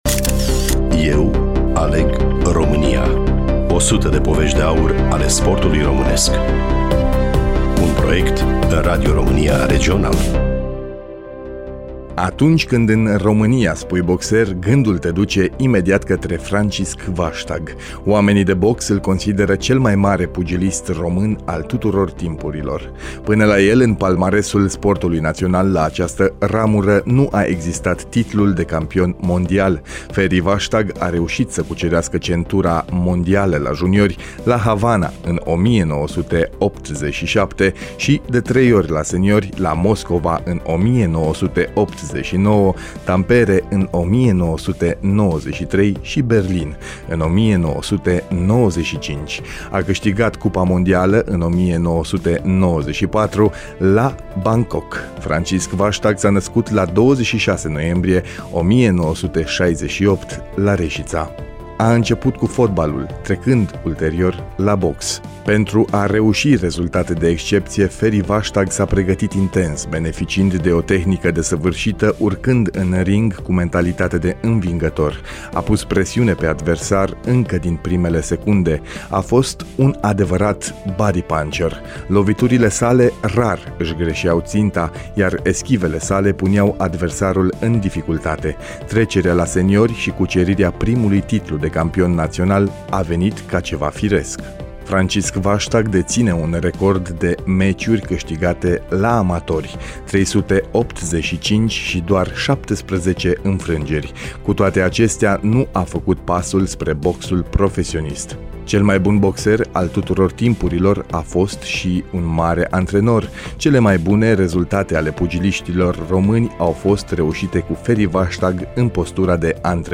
Studioul: Radio România Reşiţa